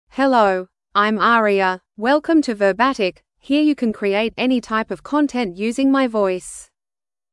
FemaleEnglish (Australia)
AriaFemale English AI voice
Aria is a female AI voice for English (Australia).
Voice sample
Listen to Aria's female English voice.
Aria delivers clear pronunciation with authentic Australia English intonation, making your content sound professionally produced.